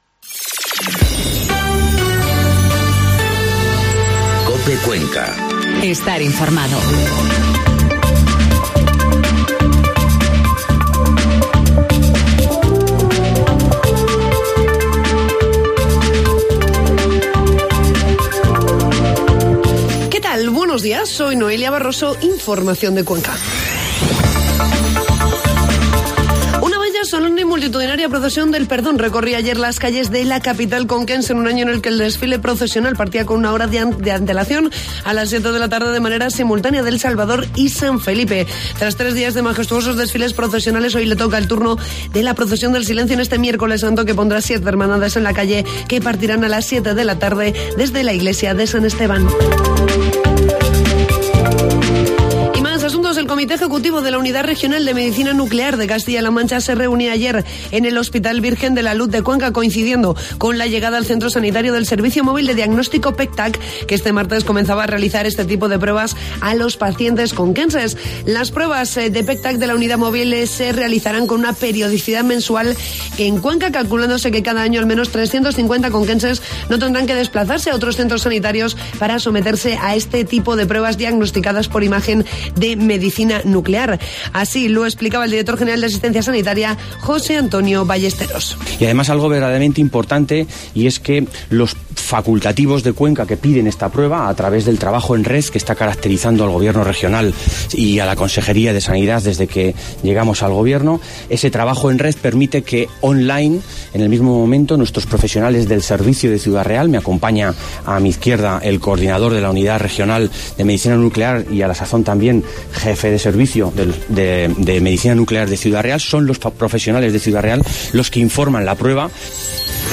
Informativo matinal COPE Cuenca 17 de abril